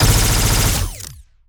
Added more sound effects.
GUNAuto_Plasmid Machinegun B Burst_04_SFRMS_SCIWPNS.wav